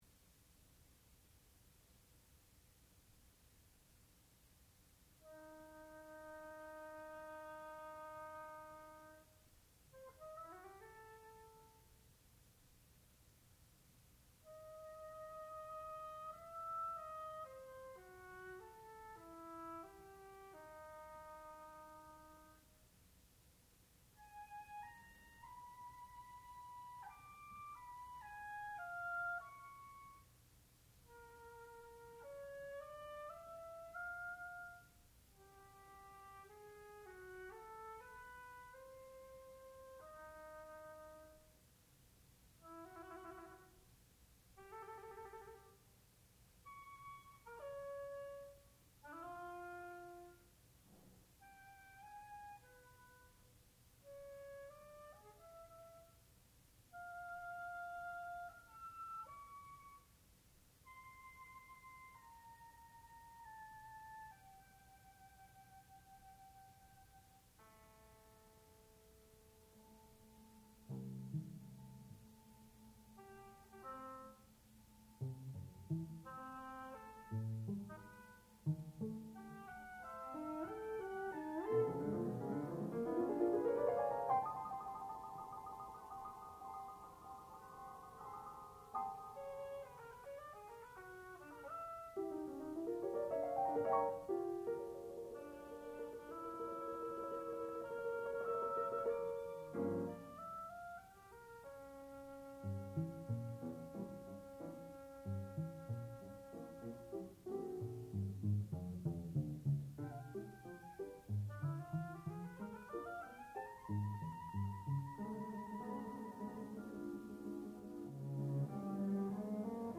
sound recording-musical
classical music
soprano